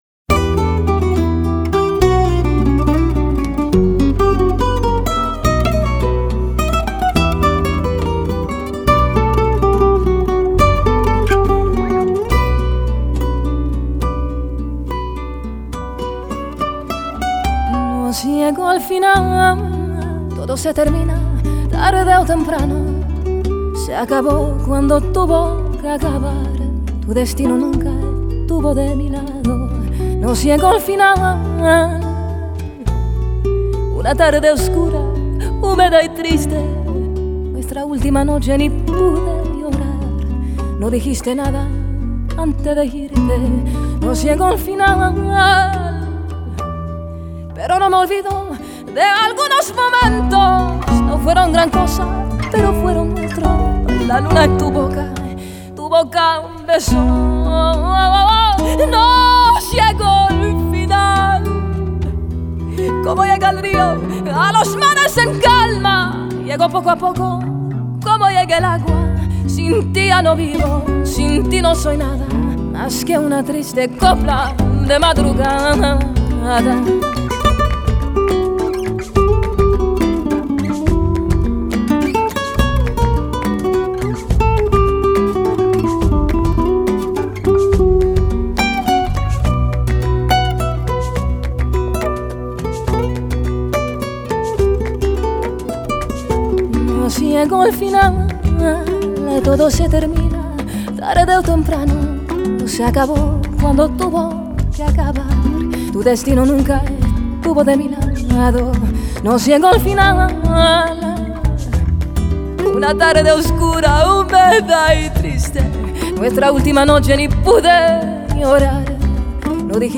Genre: Ladino